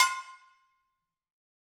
Percussion
BrakeDrum1_Hammer_v3_Sum.wav